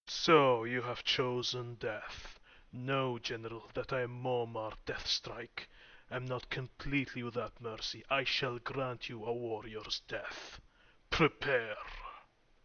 Subject description: My personal VO set   Reply with quote  Mark this post and the followings unread